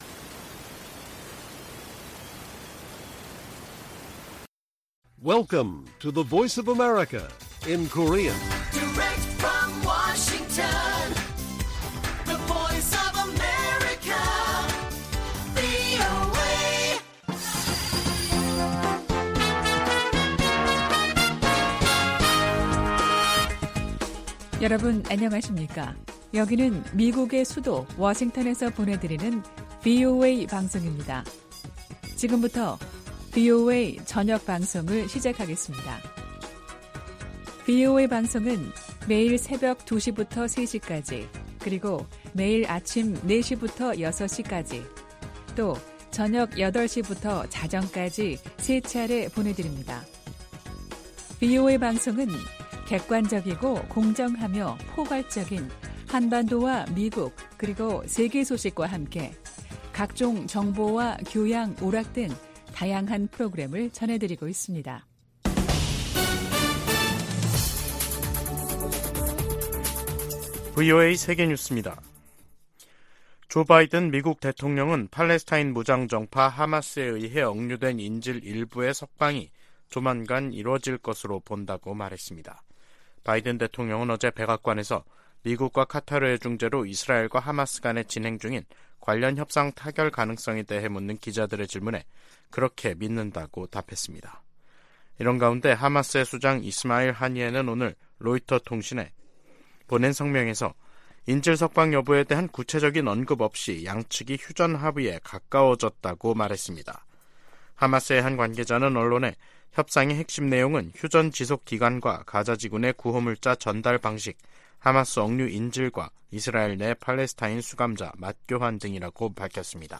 VOA 한국어 간판 뉴스 프로그램 '뉴스 투데이', 2023년 11월 21일 1부 방송입니다. 국제해사기구(IMO)는 북한이 오는 22일부터 내달 1일 사이 인공위성 발사 계획을 통보했다고 확인했습니다. 북한의 군사정찰위성 발사 계획에 대해 미 국무부는 러시아의 기술이 이전될 가능성을 지적했습니다. 한국 정부가 남북 군사합의 효력 정지를 시사하고 있는 가운데 미국 전문가들은 합의 폐기보다는 중단했던 훈련과 정찰 활동을 재개하는 편이 낫다고 진단했습니다.